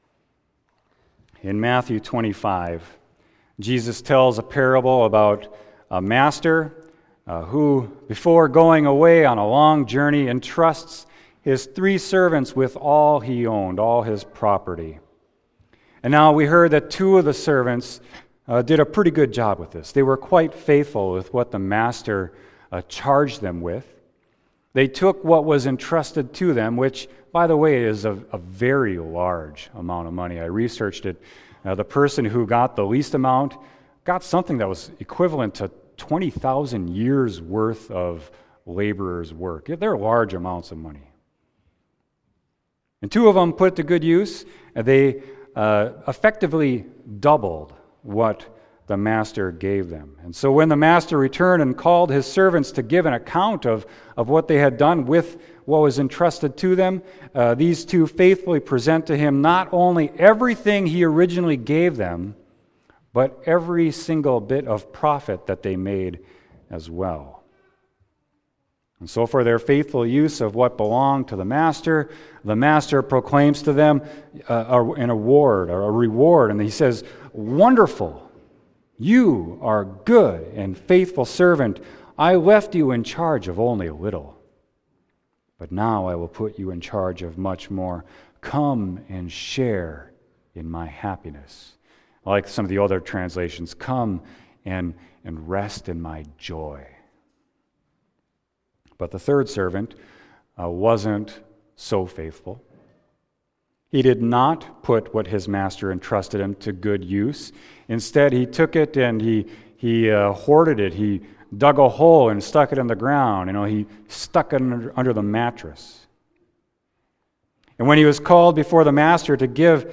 Sermon: Matthew 25.14-30